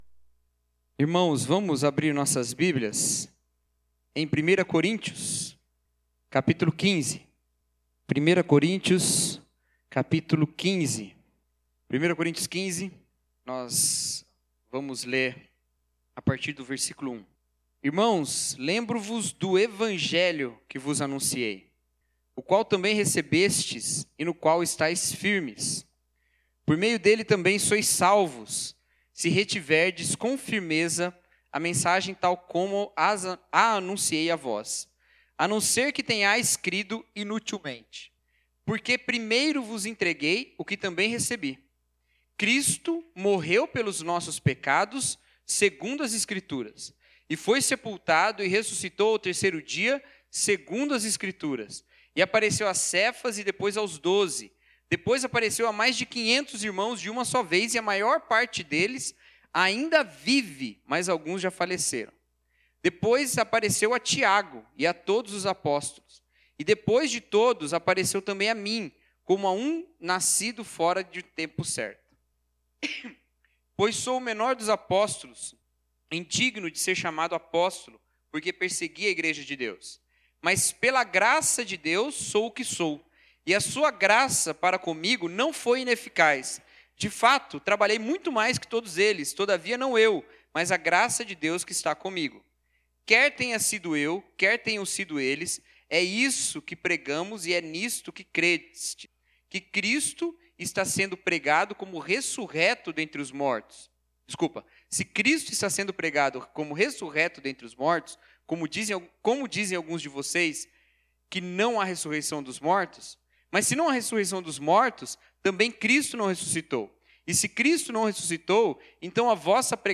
Mensagem: A Vida Ressureta